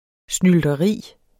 Udtale [ snyldʌˈʁiˀ ]